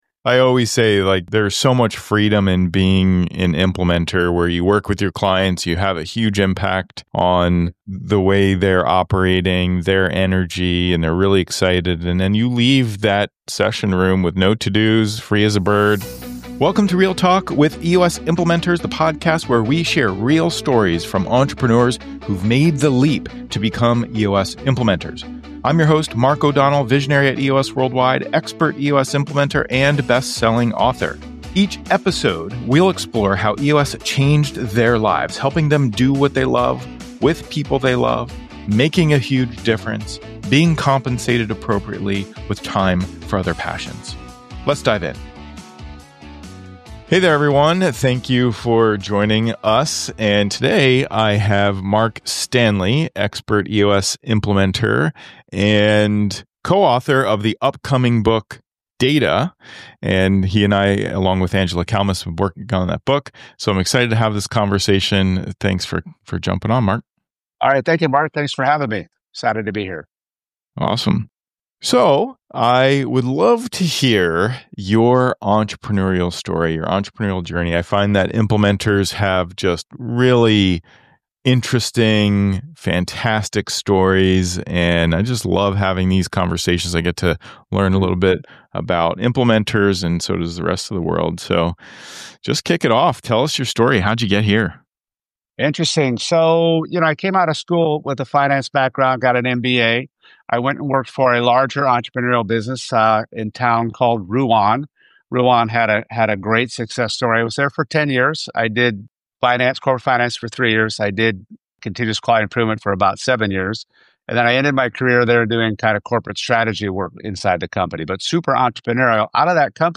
wide-ranging conversation that traces a career shaped by disruption, experimentation, and hard-earned perspective